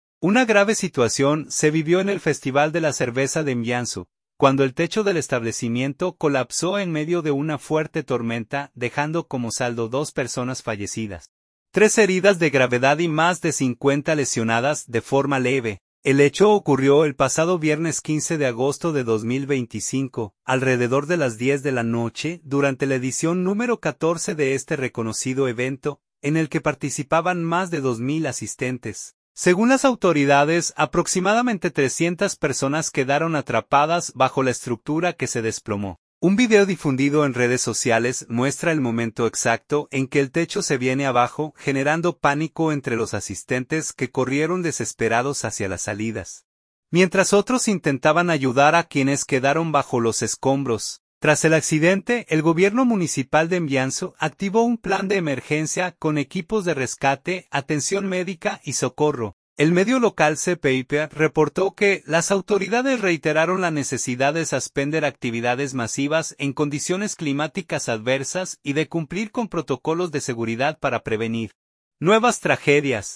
Mianzhu, China. – Una grave situación se vivió en el Festival de la Cerveza de Mianzhu, cuando el techo del establecimiento colapsó en medio de una fuerte tormenta, dejando como saldo dos personas fallecidas, tres heridas de gravedad y más de 50 lesionadas de forma leve.
Un video difundido en redes sociales muestra el momento exacto en que el techo se viene abajo, generando pánico entre los asistentes que corrieron desesperados hacia las salidas, mientras otros intentaban ayudar a quienes quedaron bajo los escombros.